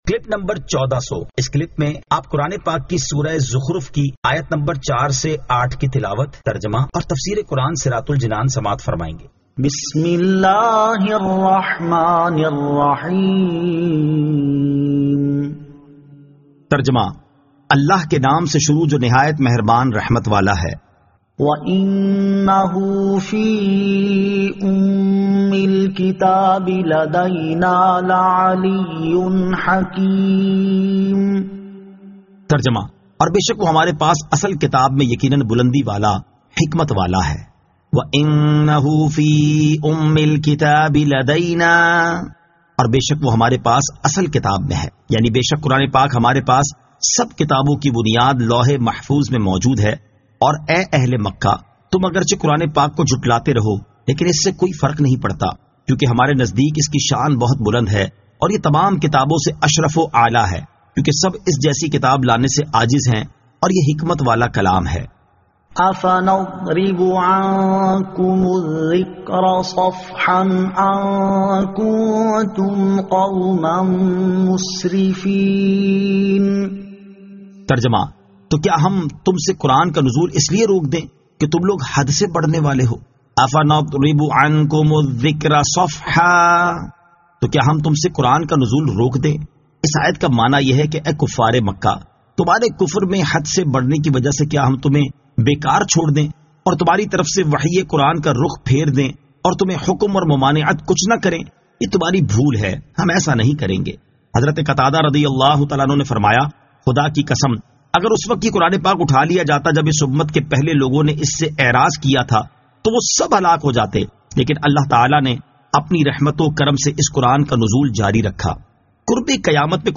Surah Az-Zukhruf 04 To 08 Tilawat , Tarjama , Tafseer